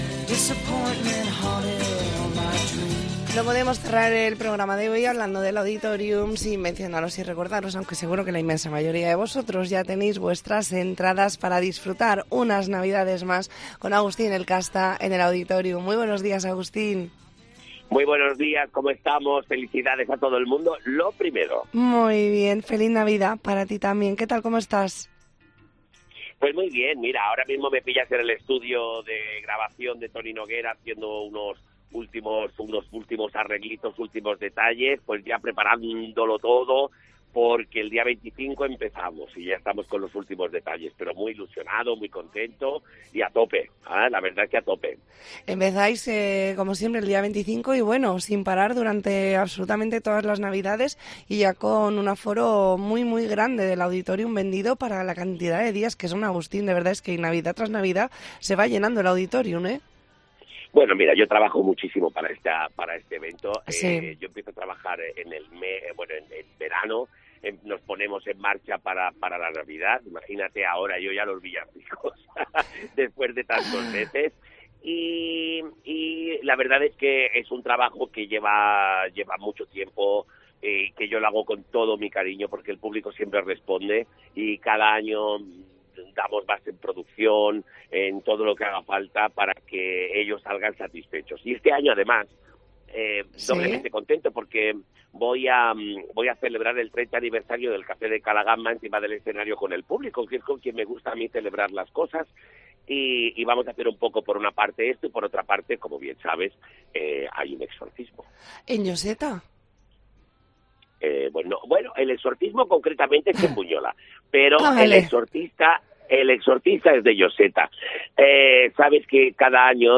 Entrevista en La Mañana en COPE Más Mallorca, jueves 21 de diciembre de 2023.